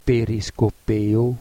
klik op het woord om de uitspraak te beluisteren